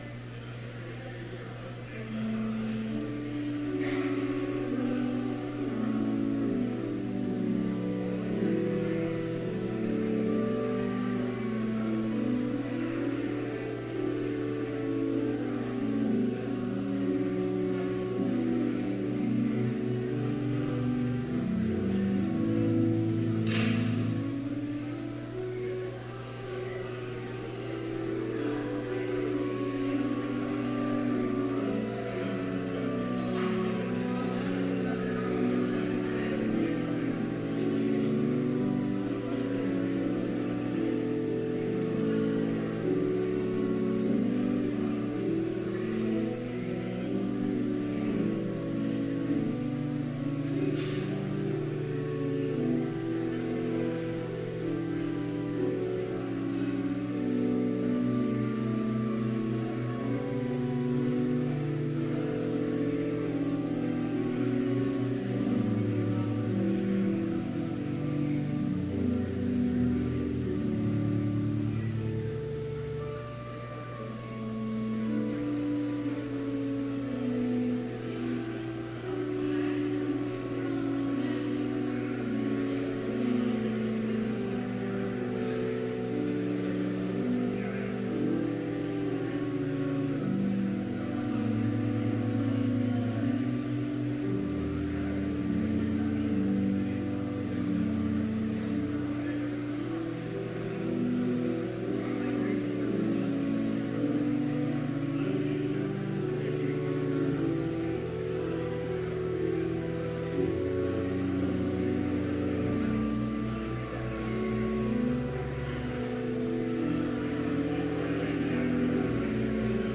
Complete service audio for Chapel - October 3, 2018